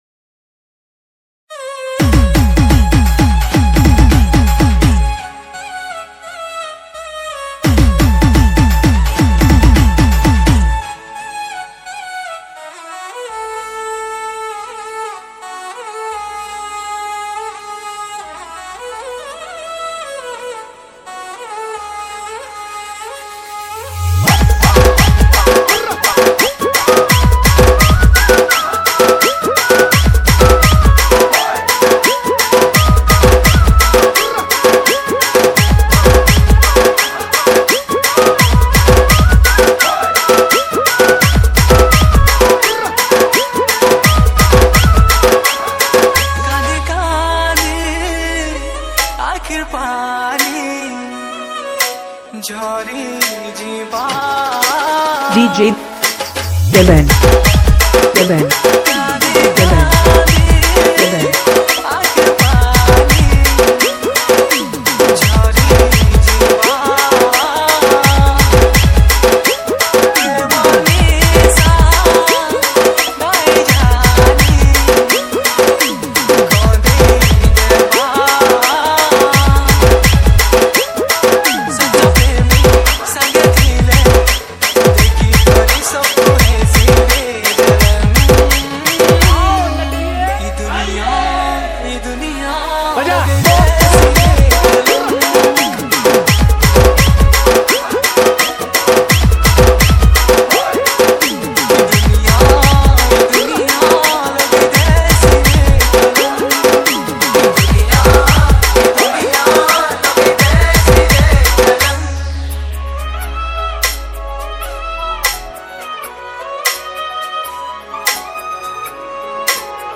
SAMBALPURI SAD DJ REMIX